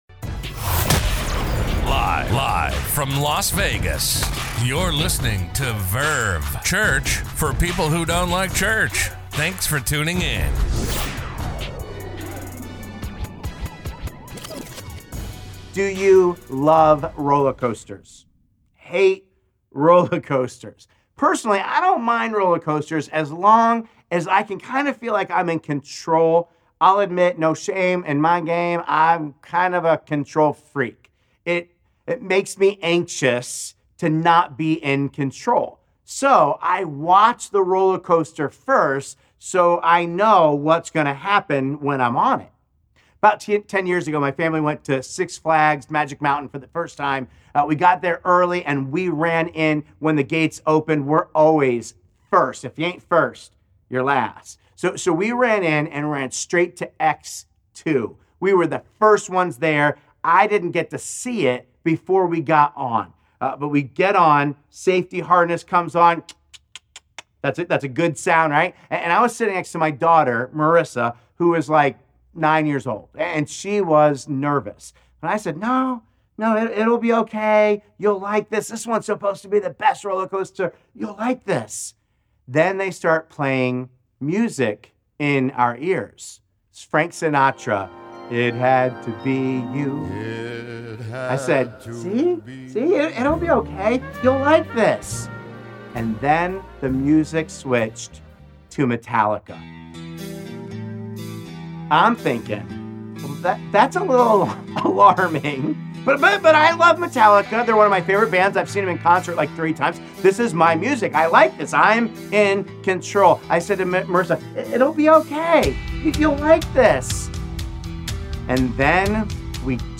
A message from the series "Anxious For Nothing."